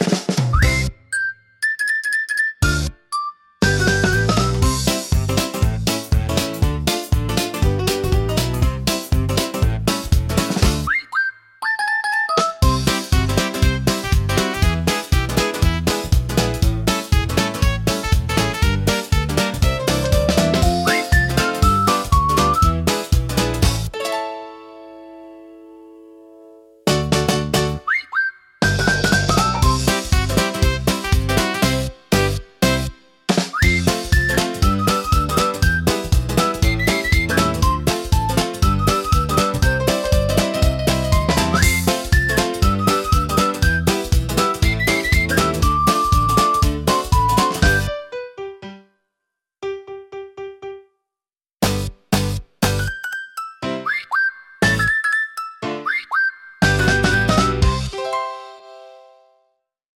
軽快なリズムと遊び心あふれるメロディが、聴く人に楽しさと自由なエネルギーを届けます。
気軽で楽しい空気を作り出し、場を明るく盛り上げるジャンルです。